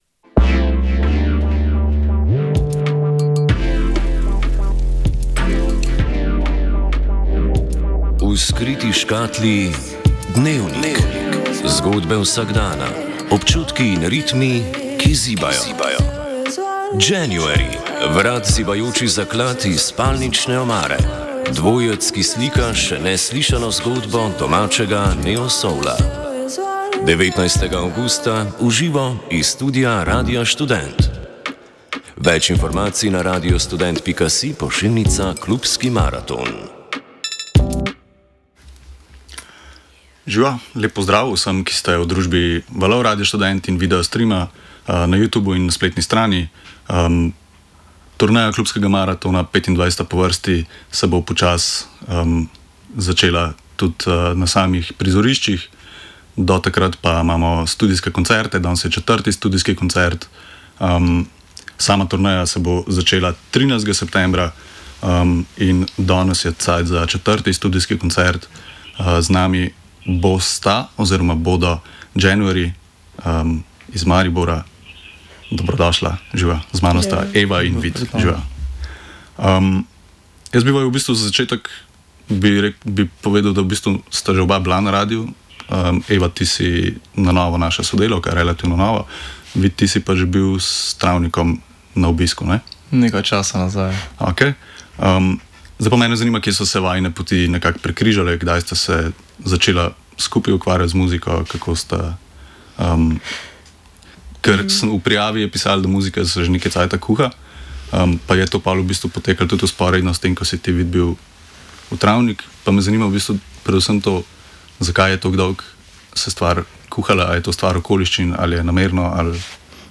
Koncert v živo | Radio Študent